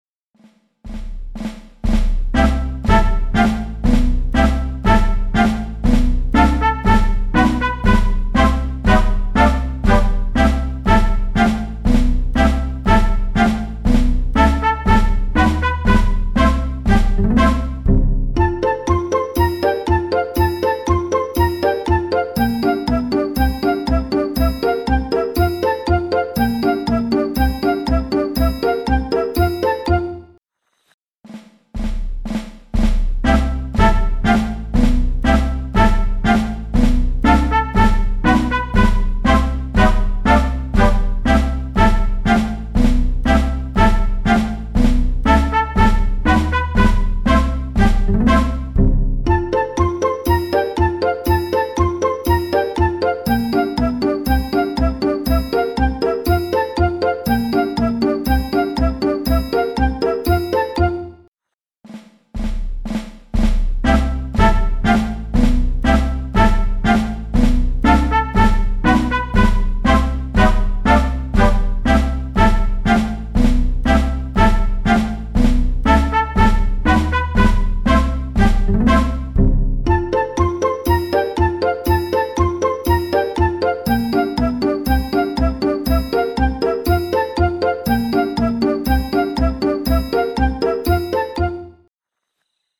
Музыка. 2-3 года Сборники песен и нот Муз.ритмические движения